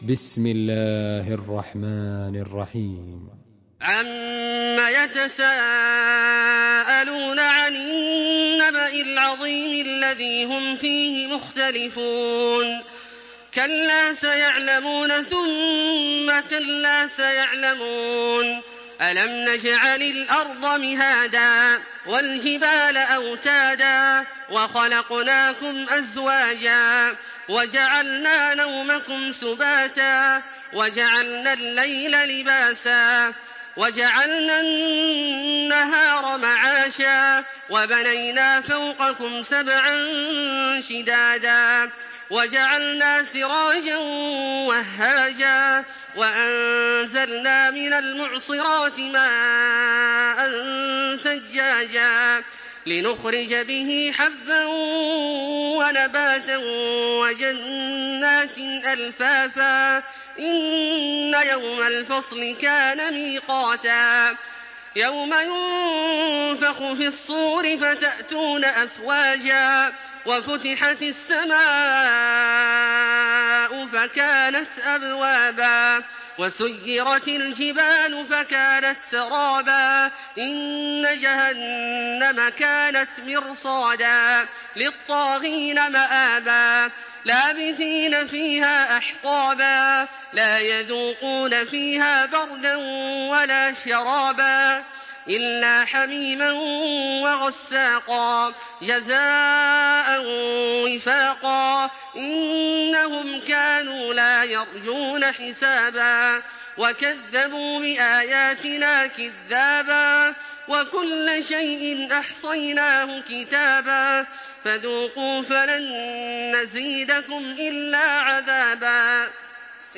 تحميل سورة النبأ بصوت تراويح الحرم المكي 1426
مرتل